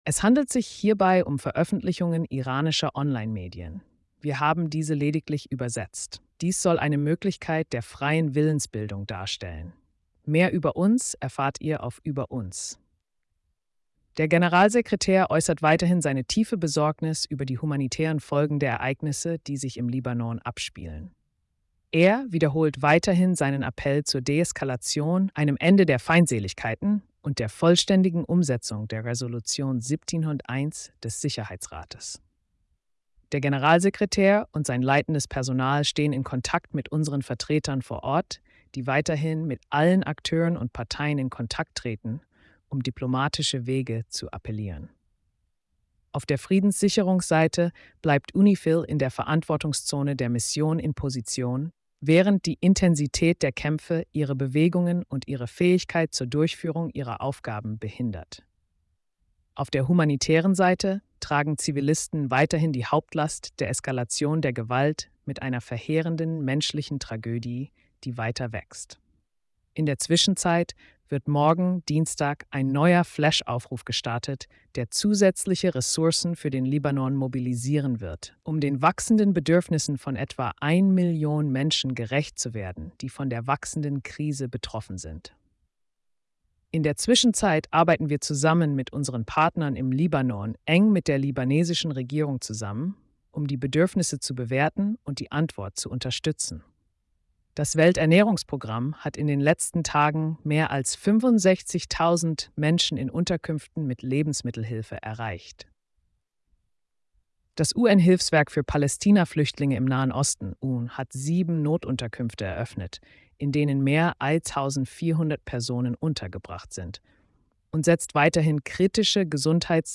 Spannende Themen: Libanon-Israel, Jemen, Haiti & mehr – Tägliche Pressekonferenz (30. Sep 2024)